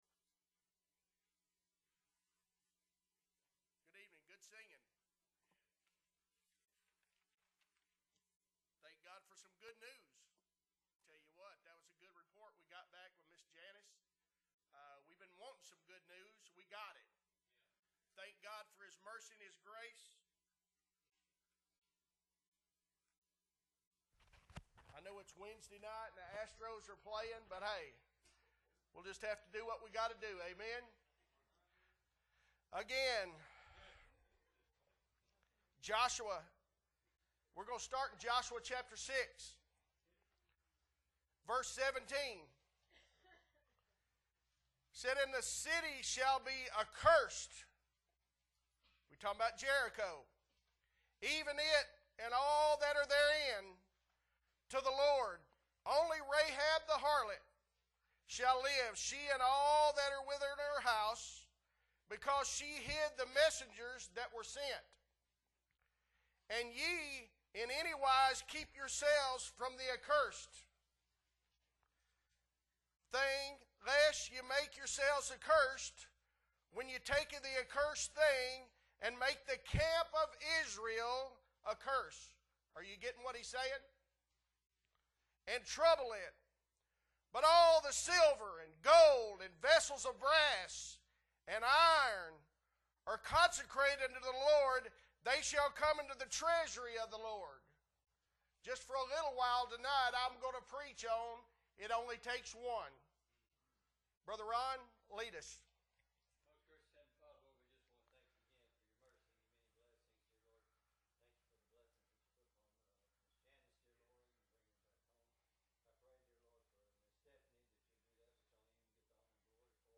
October 18, 2023 Wednesday Night Service - Appleby Baptist Church